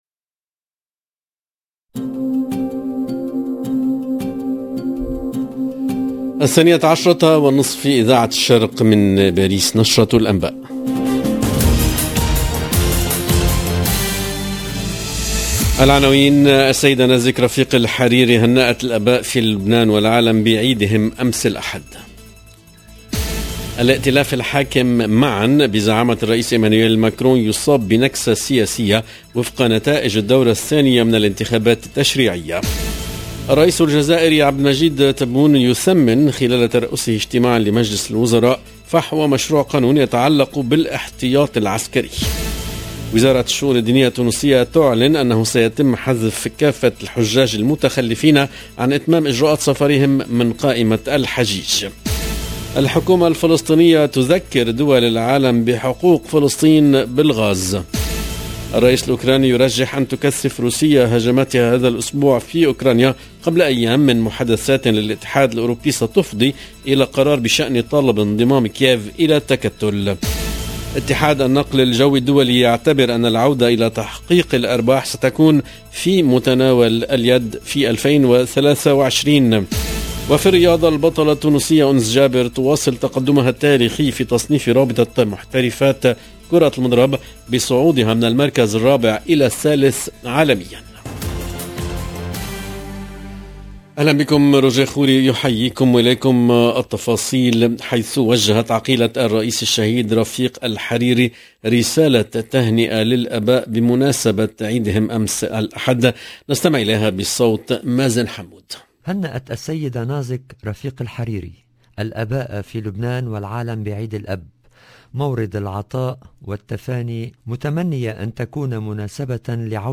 LE JOURNAL EN LANGUE ARABE DE MIDI 30 DU 20/06/22